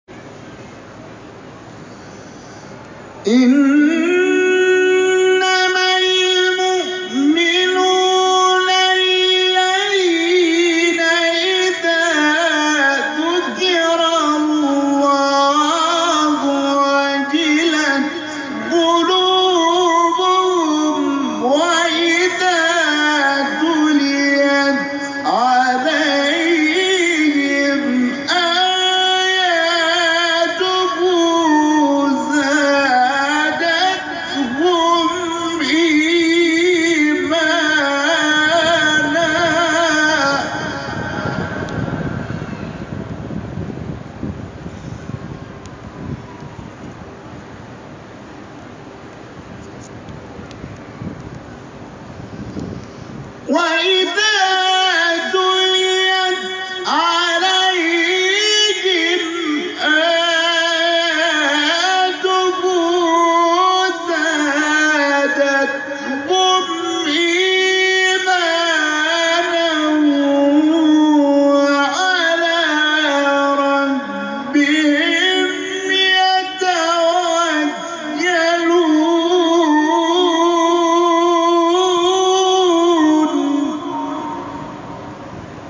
گروه شبکه اجتماعی: نغمات صوتی از تلاوت قاریان بین‌المللی و ممتاز کشور که به تازگی در شبکه‌های اجتماعی منتشر شده است، می‌شنوید.